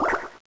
m_bubble.wav